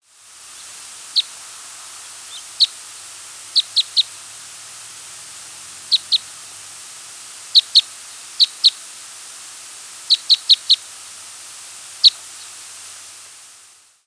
Red Crossbill flight calls
Type 3 perched.